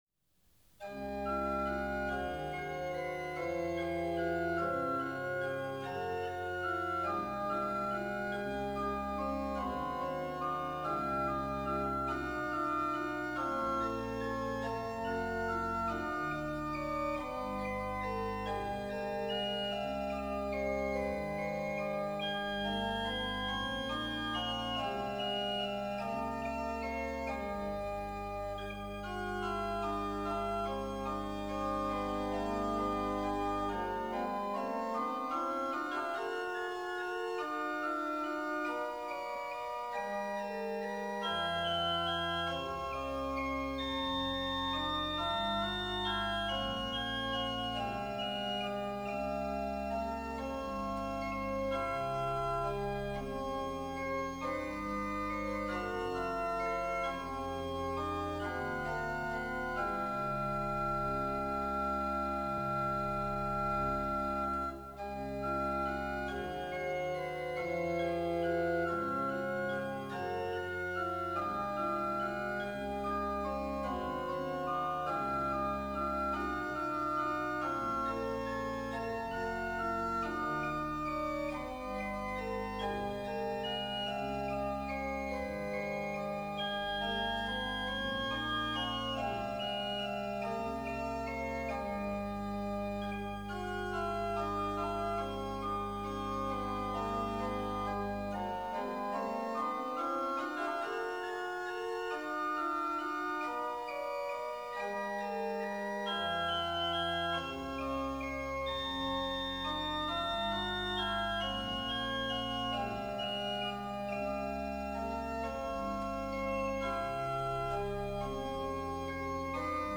Omväxlande orgel och körmusik.